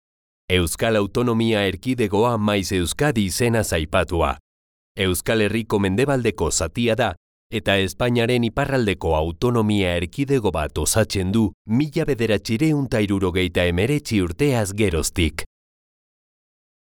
Trustworthy or irreverent personality, fresh & cool.
Sprechprobe: Sonstiges (Muttersprache):
★MY RIG★ -Mic. RODE NT1A -Preamp. Focusrite Scarlett Solo 2nd gen. -SONY MDR-7506 Headphones -DAW. Adobe Audition CC 2019 -Intel i5 / 16 RAM Gb